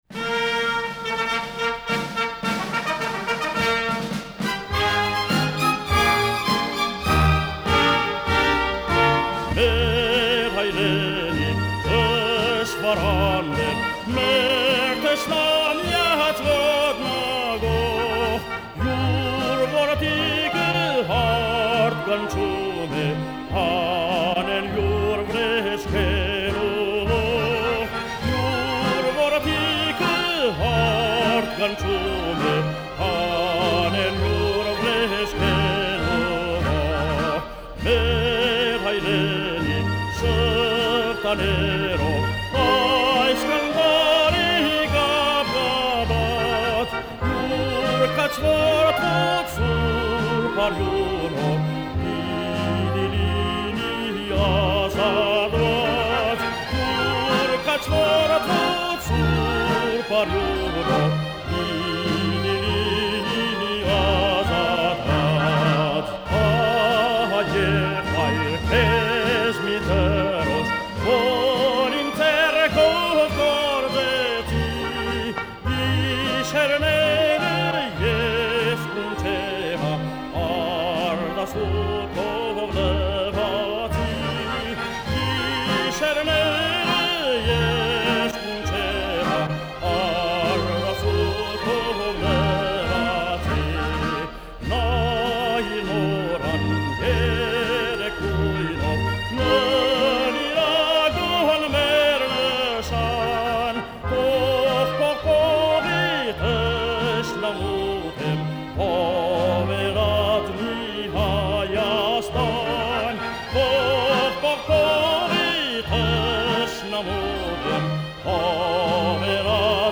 sung in Brazil